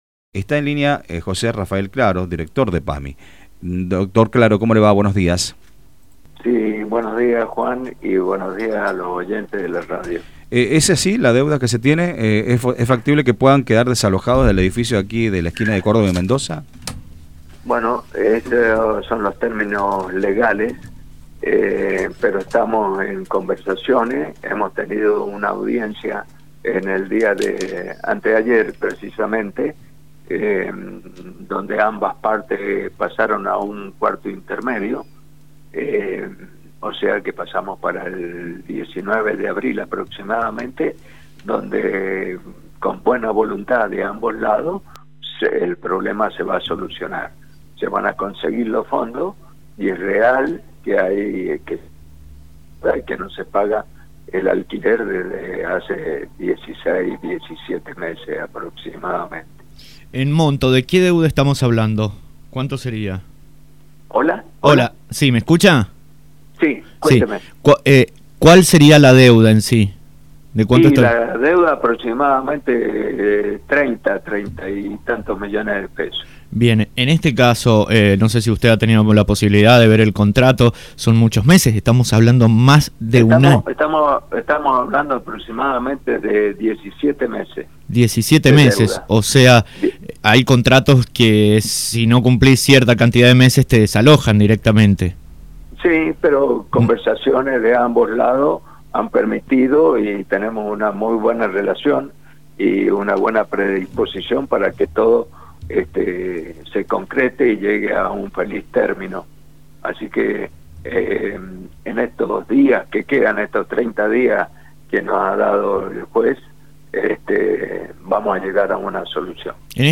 En entrevista con radio Sarmiento, el director de PAMI, Rafael Claros, manifestó que están en instancia de negociación para dar una solución favorable a este problema.